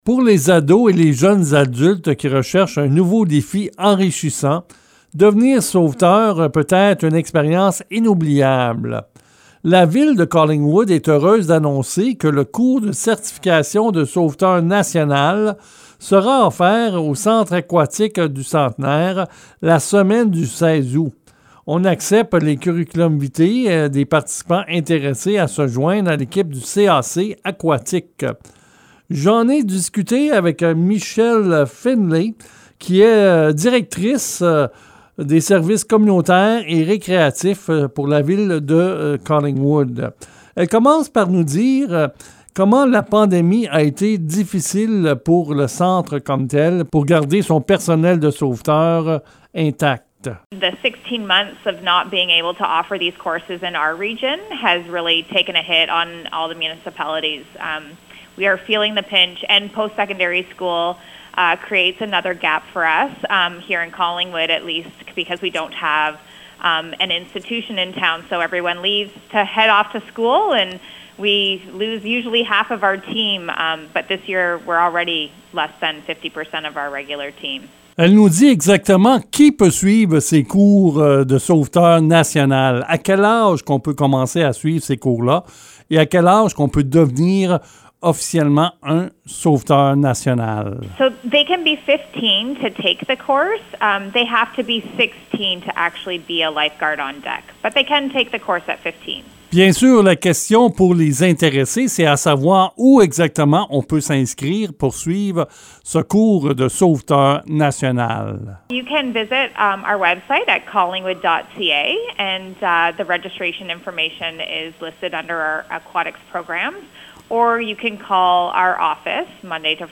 Reportage-Collingwood-cours-sauveteur.mp3